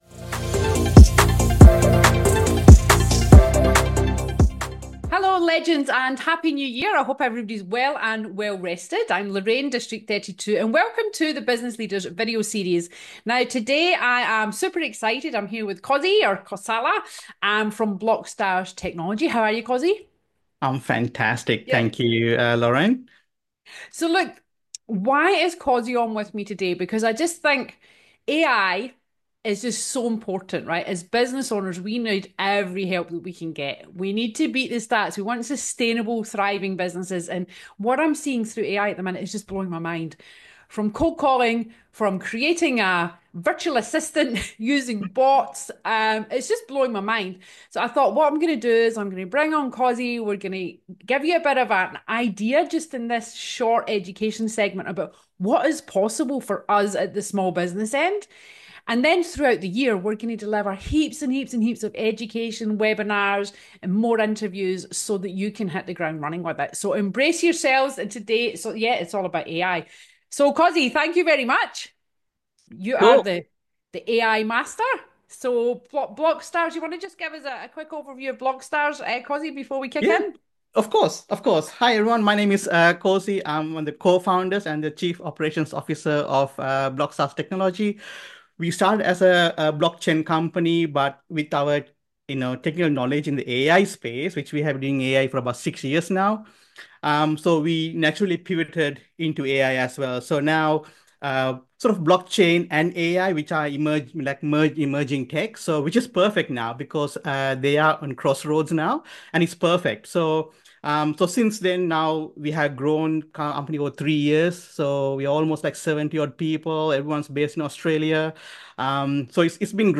This engaging conversation highlights why embracing AI is essential for staying competitive and delves into actionable strategies for optimizing workflows in sales, marketing, and productivity. You’ll gain valuable insights on data privacy, managing AI effectively, and practical tips for mastering cutting-edge tools to streamline your operations and achieve your goals.